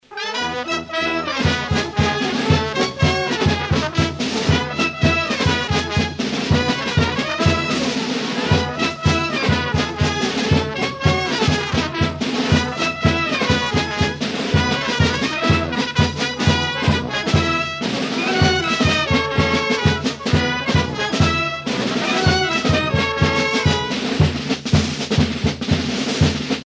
circonstance : carnaval, mardi-gras
Pièce musicale éditée